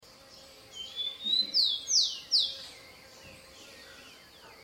O LINDO CANTO DO TICO TICO sound effects free download
O LINDO CANTO DO TICO-TICO / Zonotrichia capensis / Rufous-collared